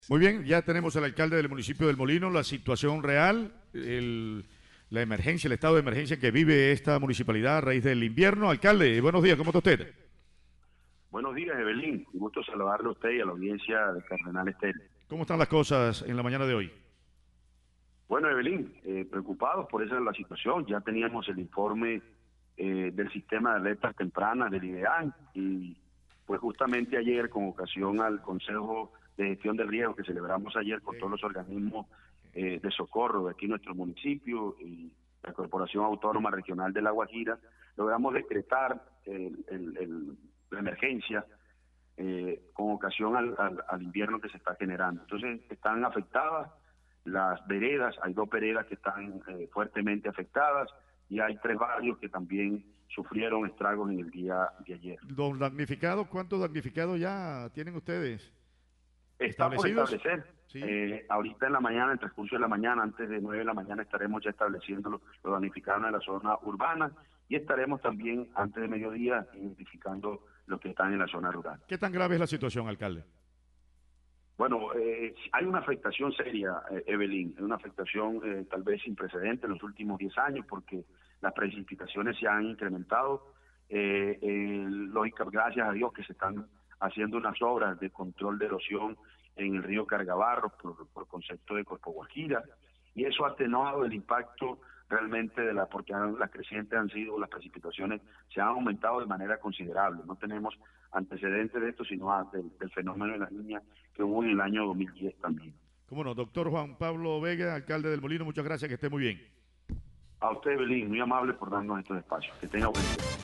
Juan Pablo Vega, alcalde municipal, contó en Cardenal Noticias que en la mañana de este jueves su administración estará haciendo un censo tanto en la zona urbana como en las veredas afectadas por la inundación.
VOZ-JUAN-PABLO-VEGA-ALCALDE-DEL-MOLINO-SOBRE-EMERGENCIA-POR-EL-INVIERNO.mp3